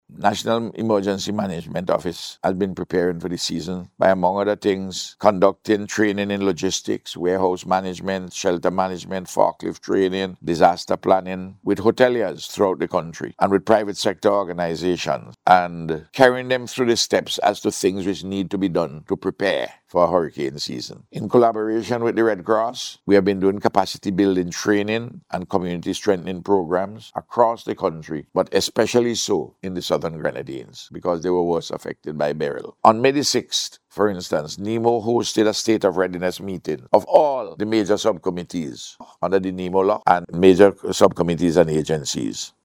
This according to Prime Minister Dr. Ralph Gonsalves who made the statement during his official message to mark the start of this year’s hurricane season.